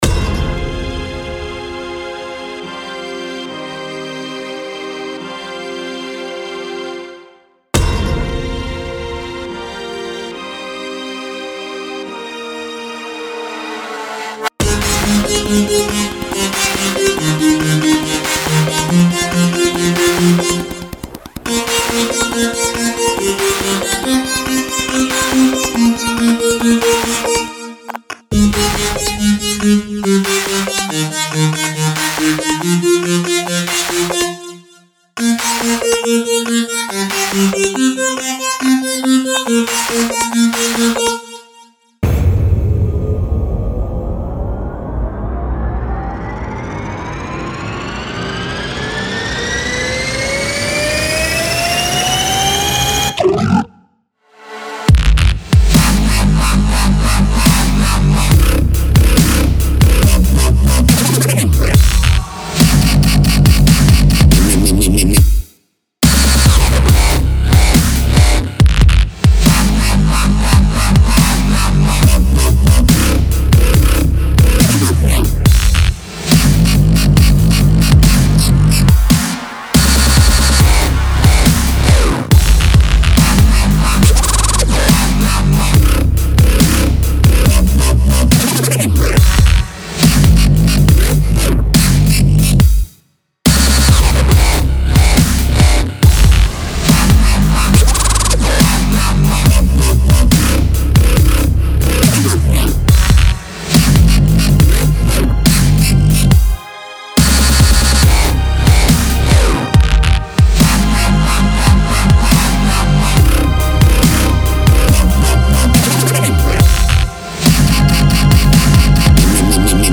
Dubstep track my first try !!
I post here a dubstep drop.
Well produced dude, liking the noises and tune.
Sounds awesome, liking the melody, sorta short though.
I can see ur Electro House style coming thru and works very well.
Make some full lengths or remixes with that bass.
New update of my WIP track, now there's a drop !